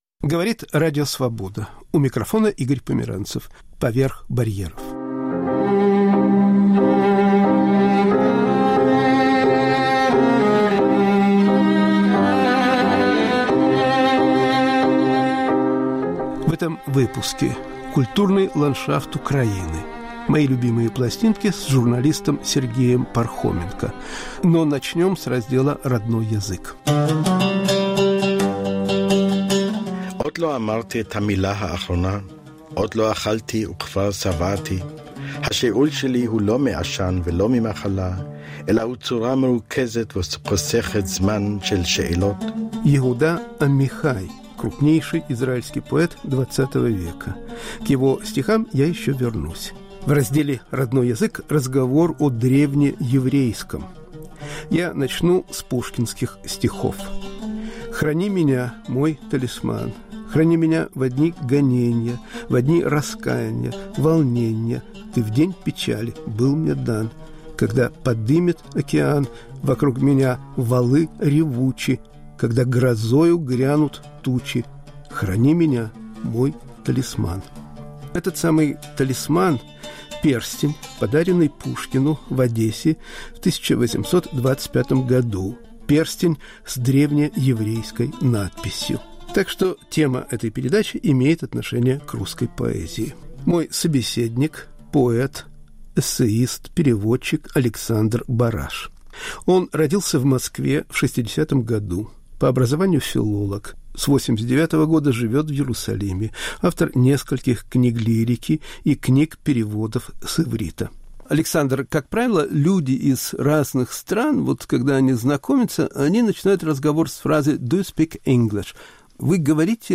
Разговор с переводчиком израильской поэзии. *** Украина: культурный ландшафт. *** Памяти Мариэтты Чудаковой.*** "Мои любимые пластинки" с журналистом Сергеем Пархоменко.